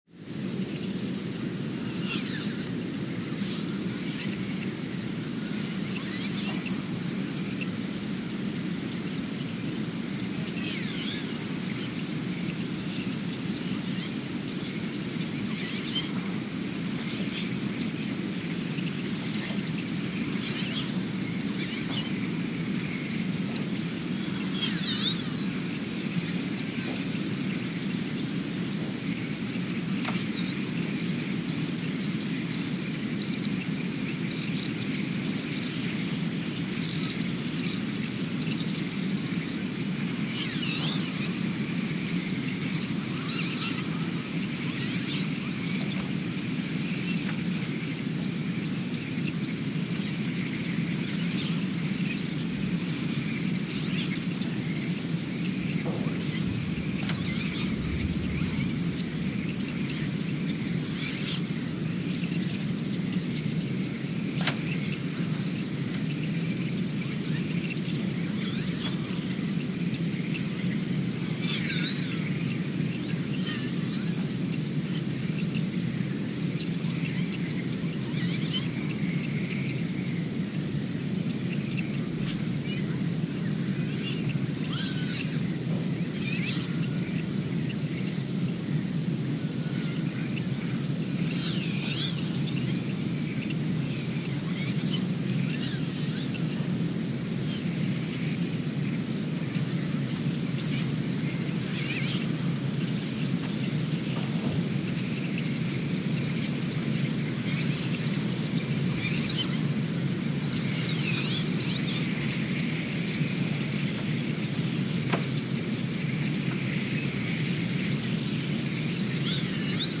Ulaanbaatar, Mongolia (seismic) archived on September 17, 2023
Sensor : STS-1V/VBB
Speedup : ×900 (transposed up about 10 octaves)
Loop duration (audio) : 03:12 (stereo)